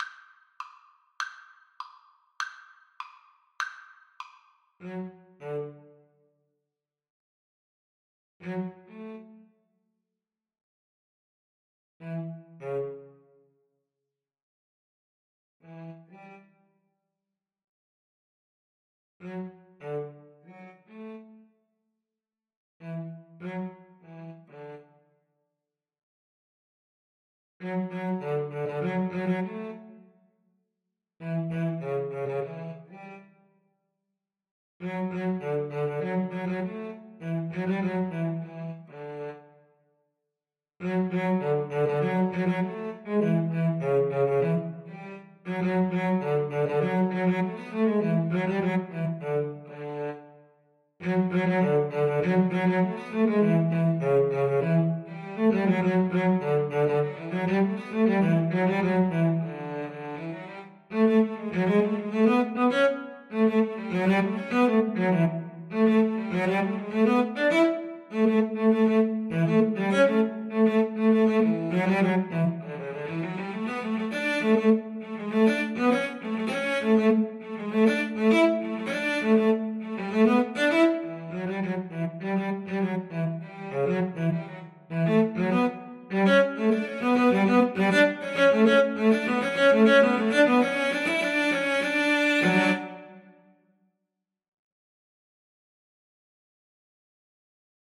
Moderato e ritmico =c.100
2/4 (View more 2/4 Music)
Cello Duet  (View more Intermediate Cello Duet Music)
Classical (View more Classical Cello Duet Music)